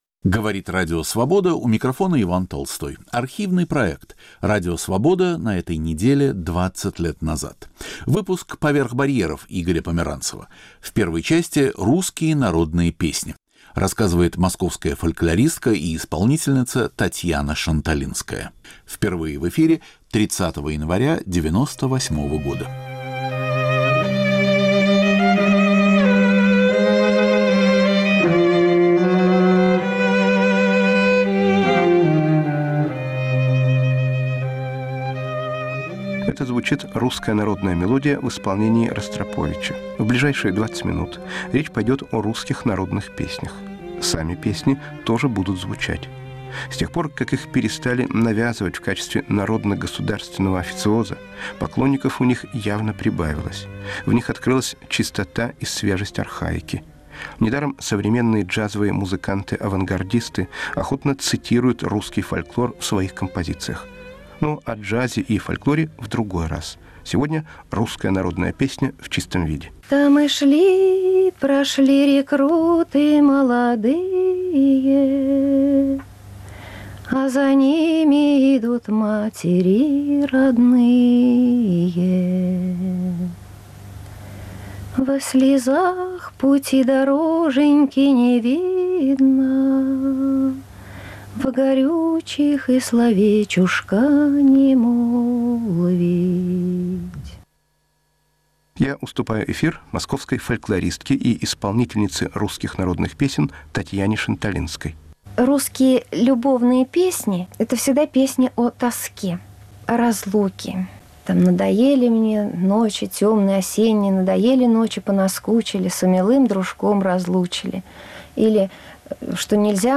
Рассказывает и поет фольклорист